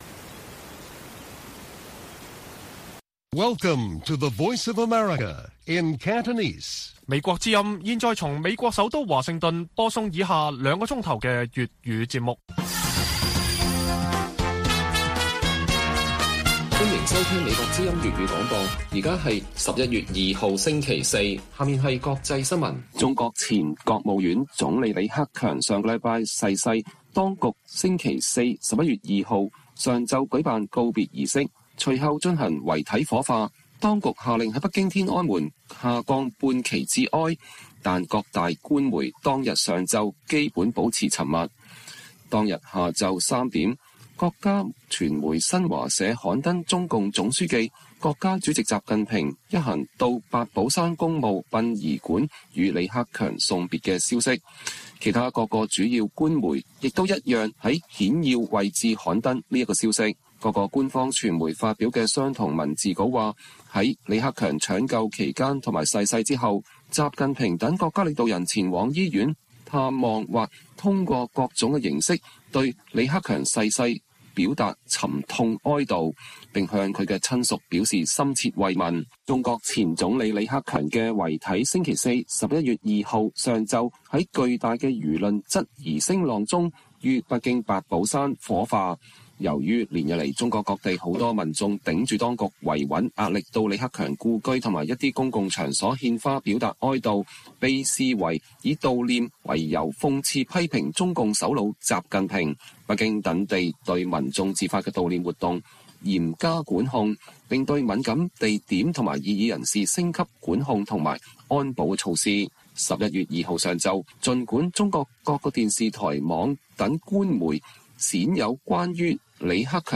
粵語新聞 晚上9-10點: 中國當局嚴密管控為李克強舉行火葬